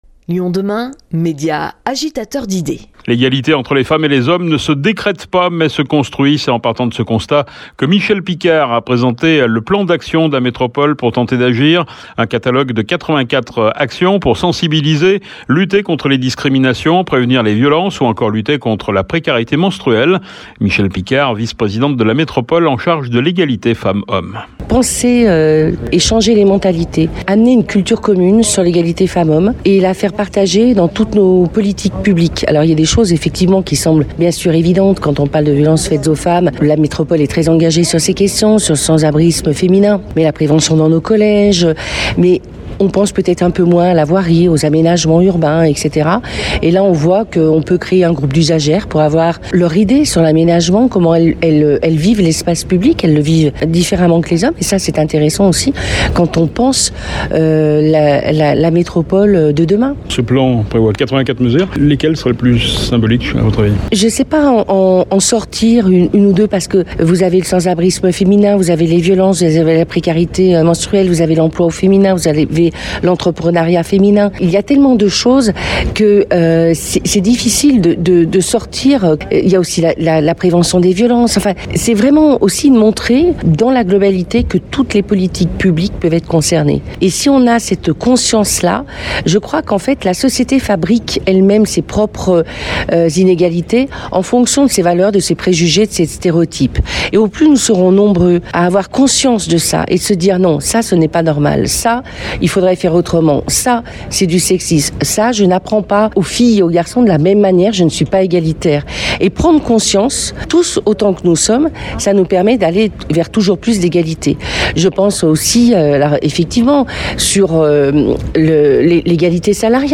Nous avons rencontré Michèle Picard, vice-présidente de la Métropole en charge de l’égalité femmes-hommes…